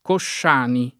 [ košš # ni ]